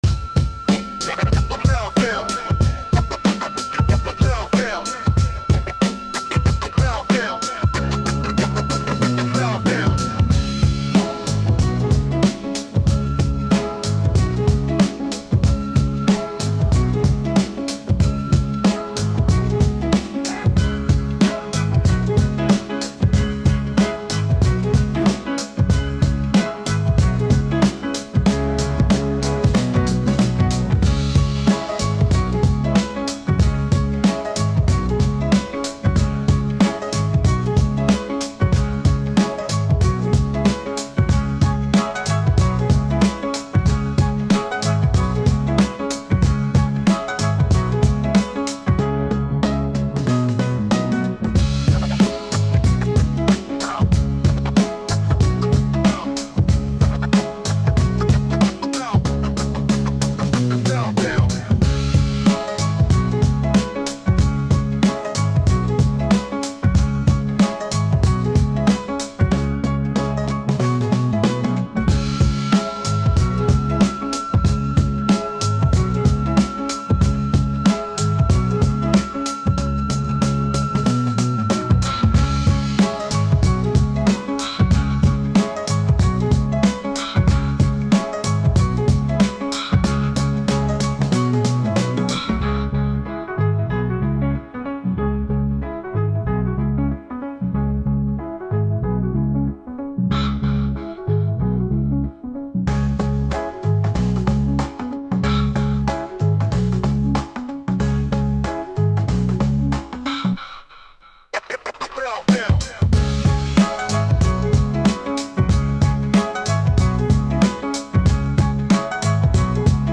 your ultimate destination for calming vibes, chill beats
lo-fi music